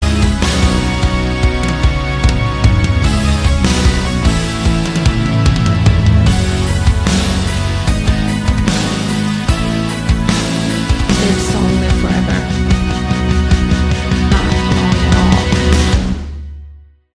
Category: Melodic Rock
keyboards, vocals
guitar, keyboards
bass
drums